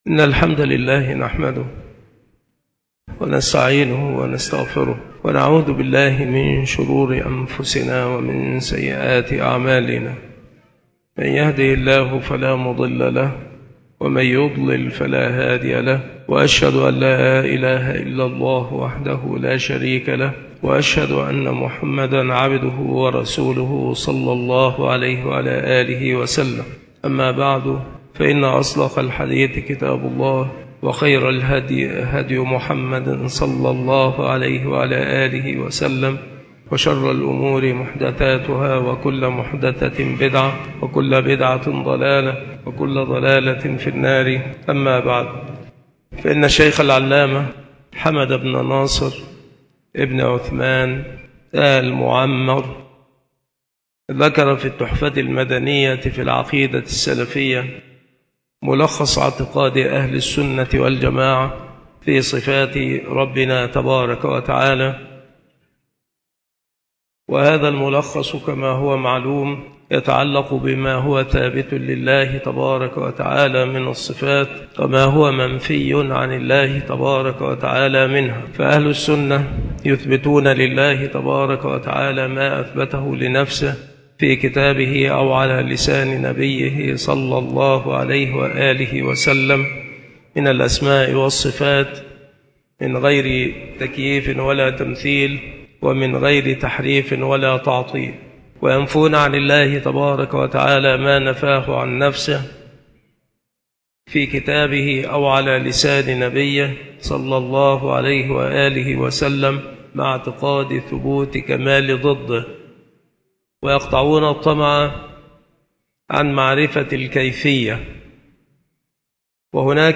مكان إلقاء هذه المحاضرة بالمسجد الشرقي بسبك الأحد - أشمون - محافظة المنوفية - مصر عناصر المحاضرة : ما تنازع فيه المتأخرون من ألفاظ. باب في الجهة. فصل في إثبات اليد.